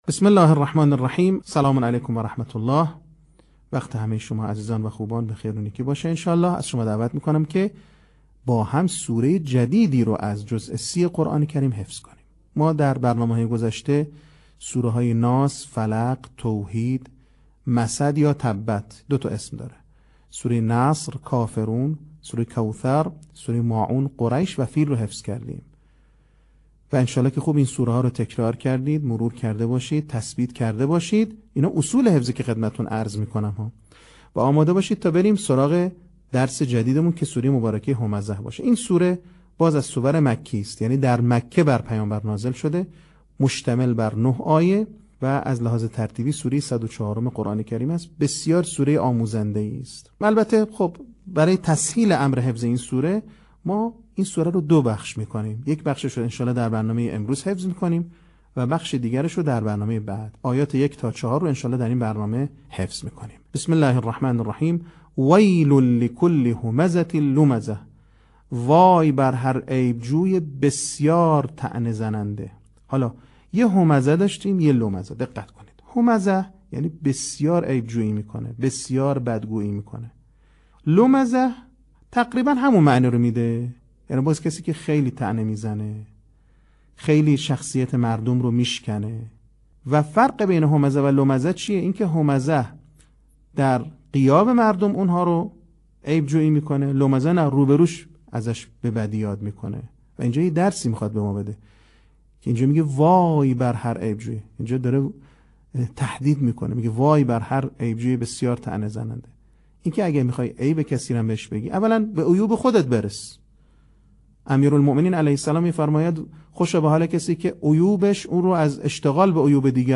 صوت | آموزش حفظ سوره همزه